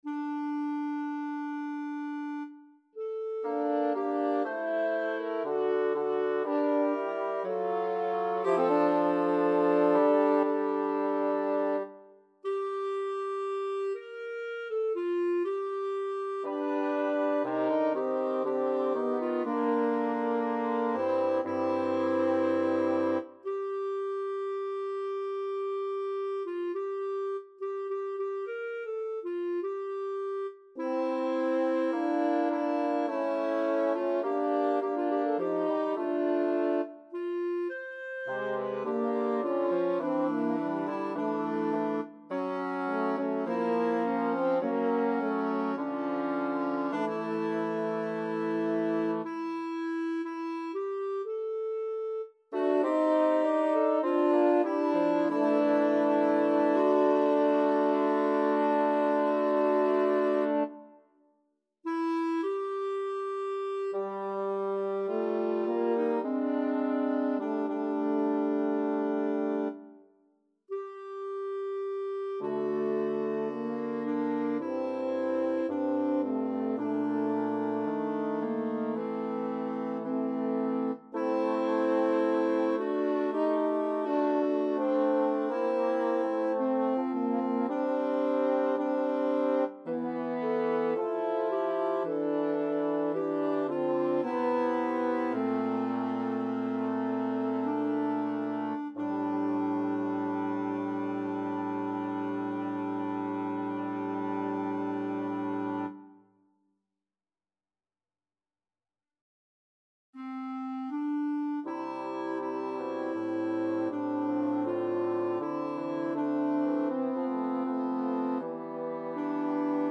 Number of voices: 3vv Voicing: ATB Genre: Sacred, Preces and Responses
Language: English Instruments: A cappella